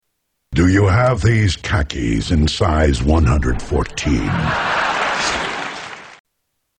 Tags: Television Giant Robot Voice Top 10 David Letterman Optimus Prime Late Show with David Letterman